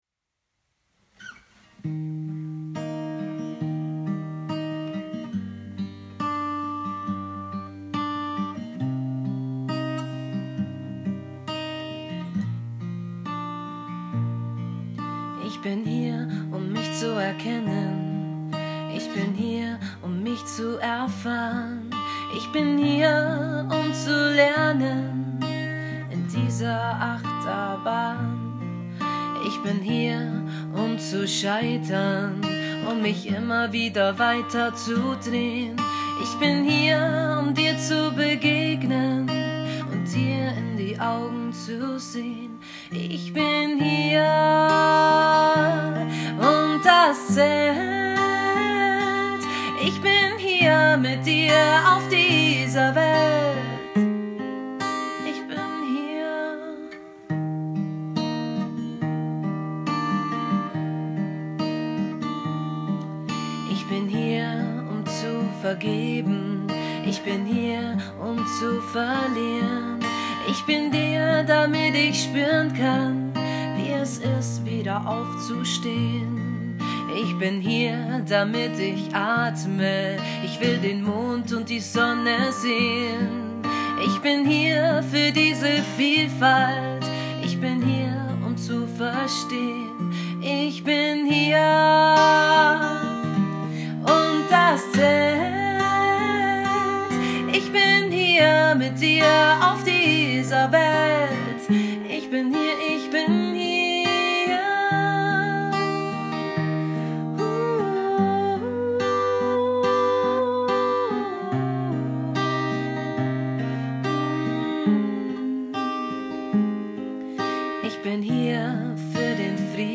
schwingungserhöhende Klänge